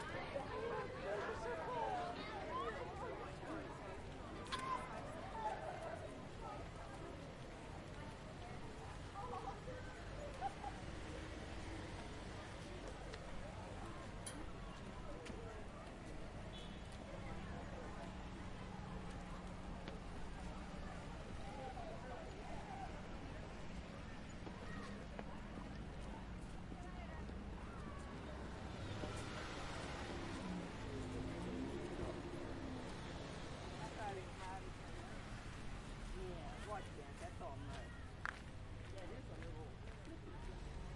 蟋蟀树夜
描述：晚上在树上发现的蟋蟀。2008年8月30日晚上10点左右在纽约州布法罗市录制的。